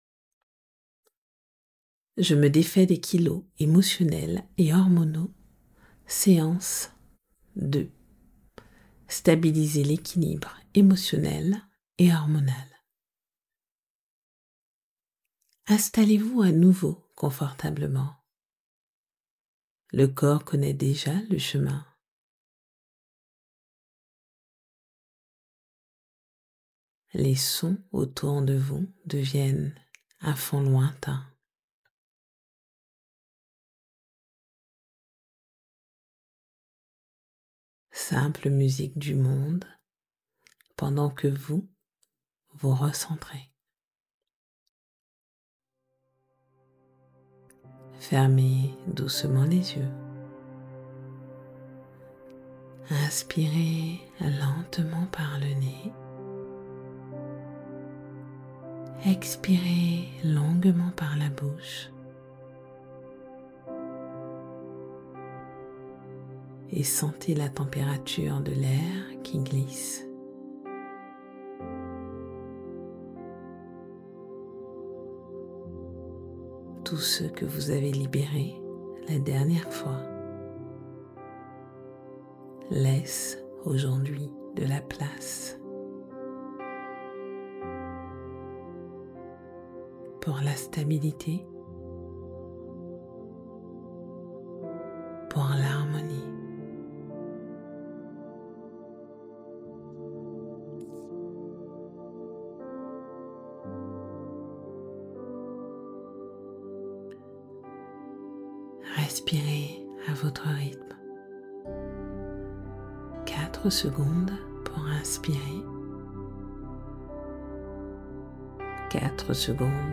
Audios de sophrologie, hypnose et coaching pour apaiser le stress, cultiver la confiance et retrouver ton équilibre, où que tu sois, à ton rythme.